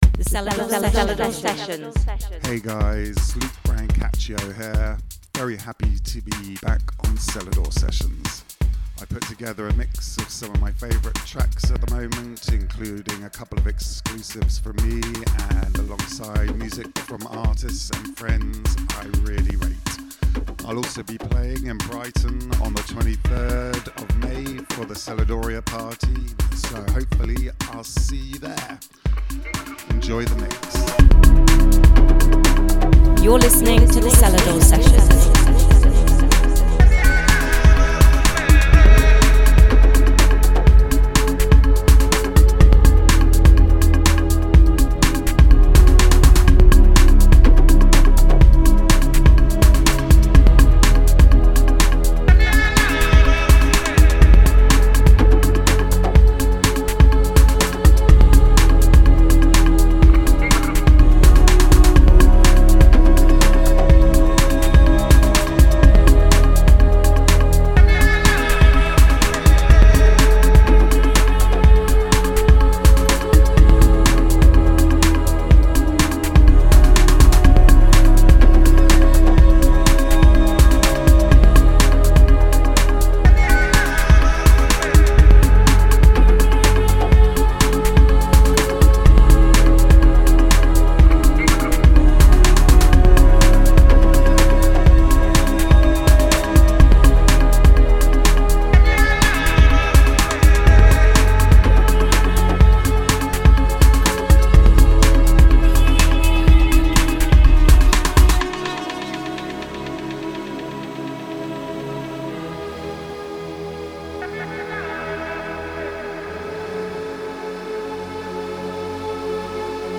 music DJ Mix in MP3 format
Genre: Progressive house